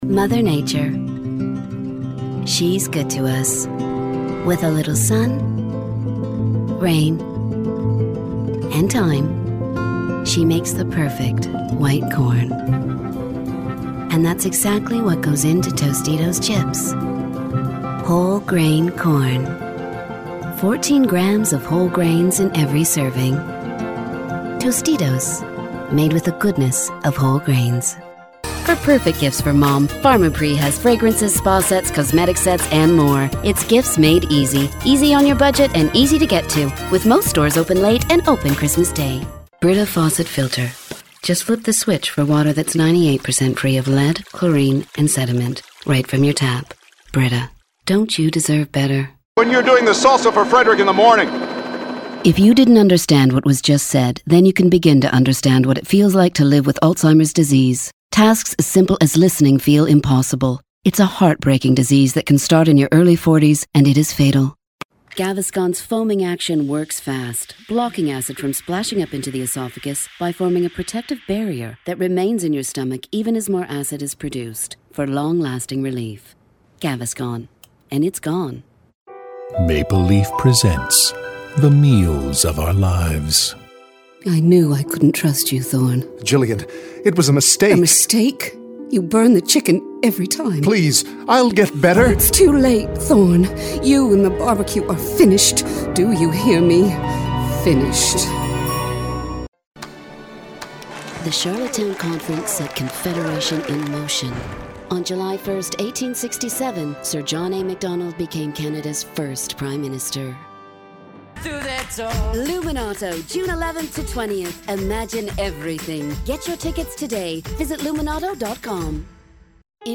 Unique female voice over professional, from sultry and smooth, to wry and conversational
Sprechprobe: Werbung (Muttersprache):
Unique Female Voice Talent